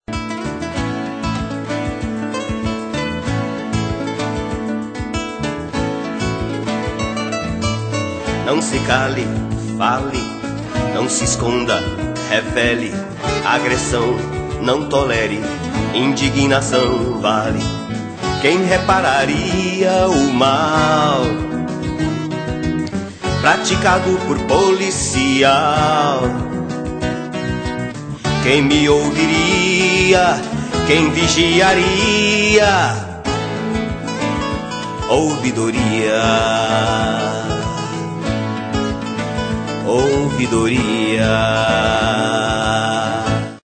Faixa 8 - MPB 3